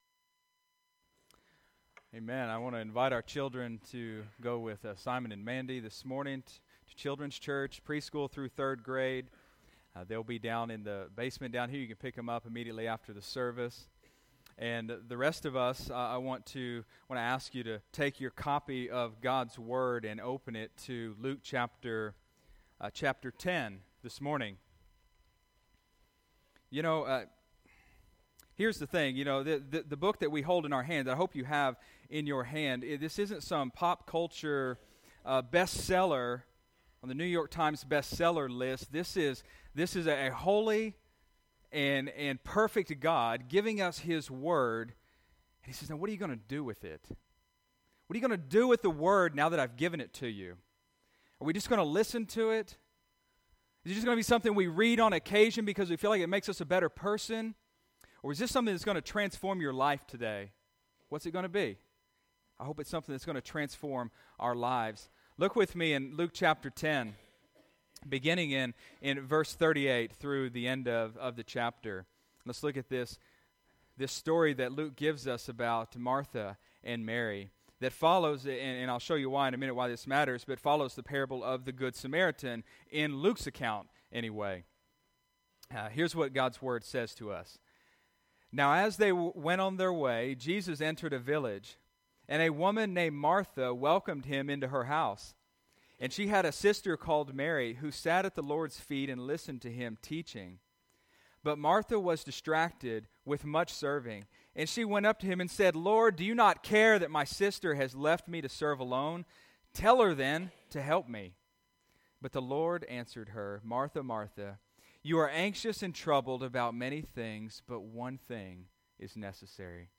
Sunday, March 22, 2015 (Sunday Morning Service)